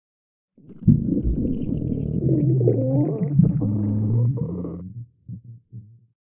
digest_04.ogg